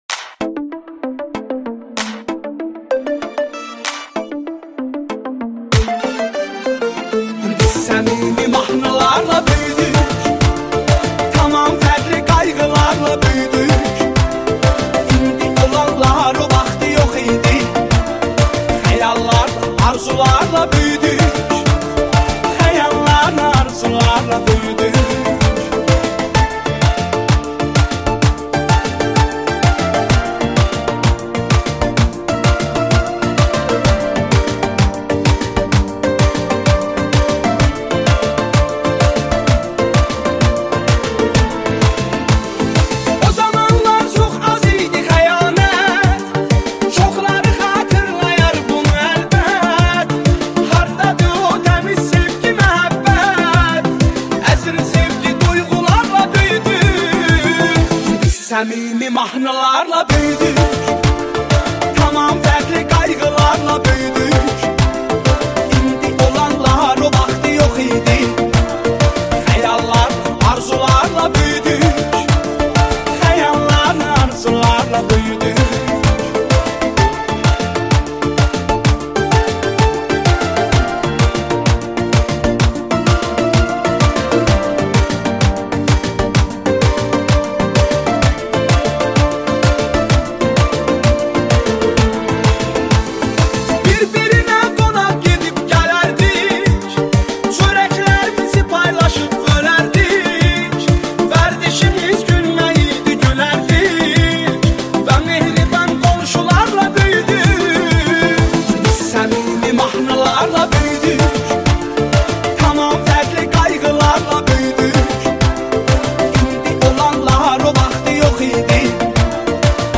موزیک آذری